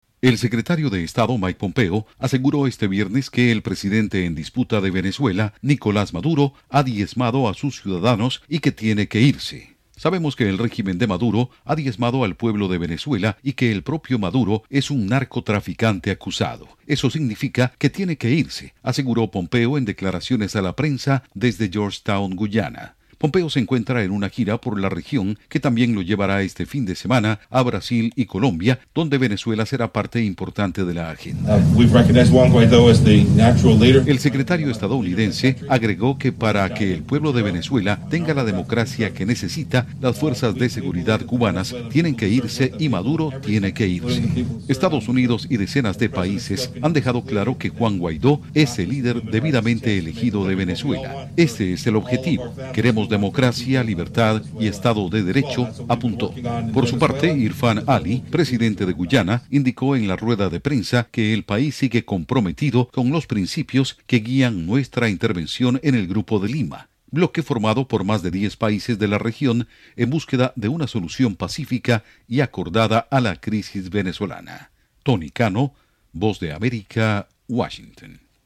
Pompeo sobre Venezuela durante visita a Guyana